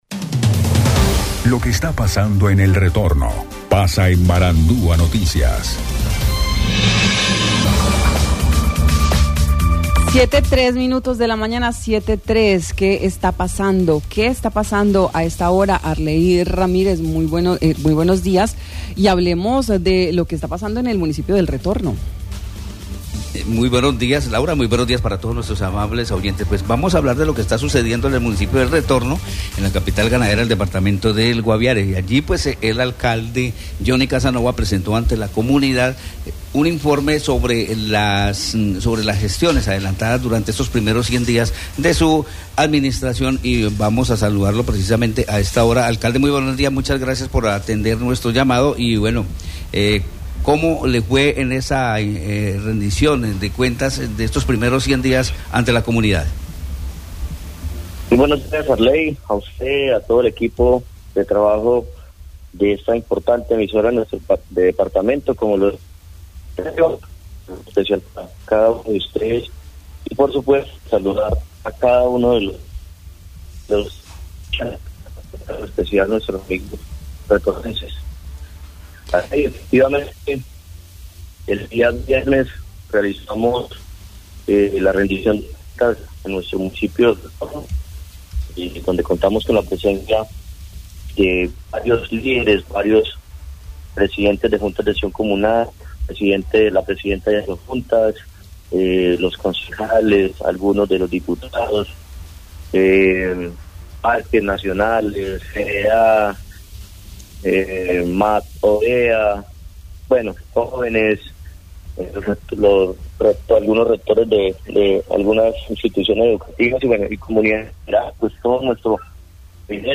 El alcalde de El Retorno Guaviare, Johnny Casanova, señaló en Marandua Noticias que en estos primeros 100 días de administración destaca que cuenta con un buen equipo de trabajo y el apoyo que ha recibido del gobernador Yeison Rojas.